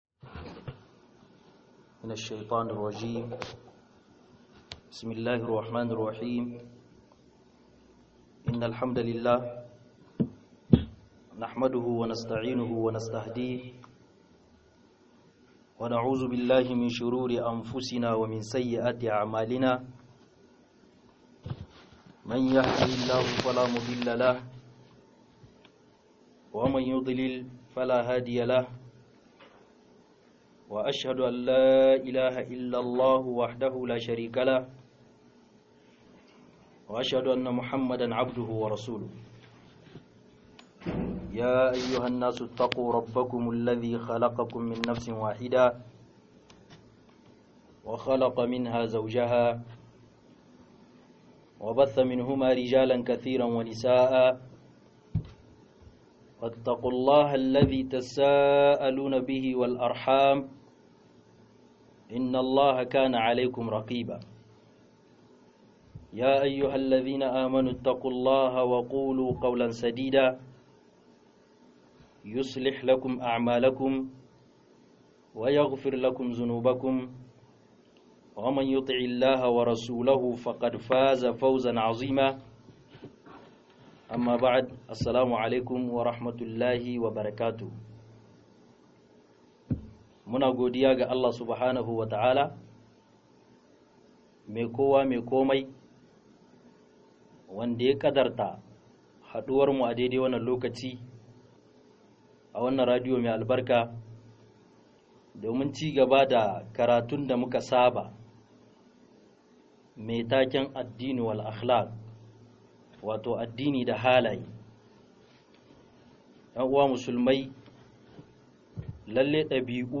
01 ADINI-DA-HALAYE-MASU-KEW - MUHADARA